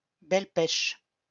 Belpech (French pronunciation: [bɛlpɛʃ]